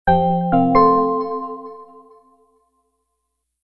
logon-a.mp3